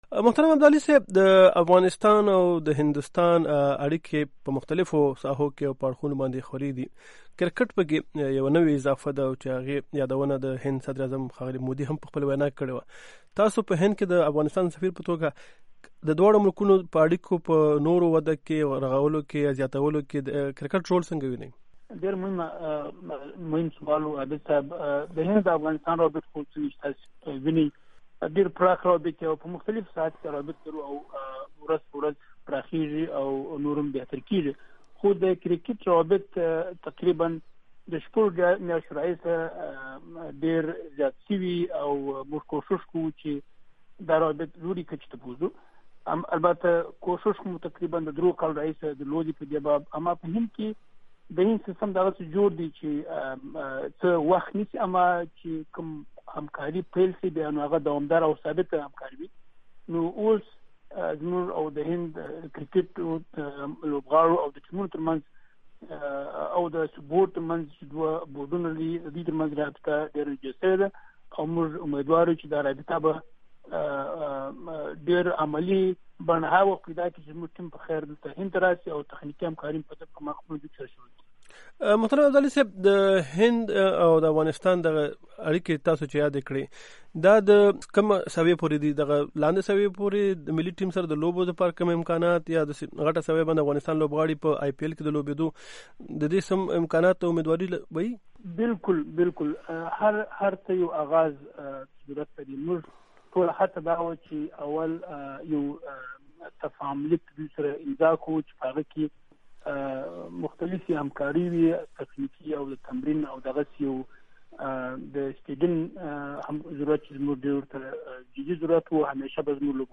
مرکې
د افغانستان او هند ترمنځ د کلتوري او اقتصادي اړیکو د دوام سره سره اوس د کرکټ د لارې هم د دواړو هیوادونو ترمنځ د اړیکو یو نوی دور پیل شویدی. دا خبره په هند کې د افغانستان سفیر ډاکټر شېدا محمد ابدالي د امریکا غږ آشنا راډیو سره مرکه کې کړیده.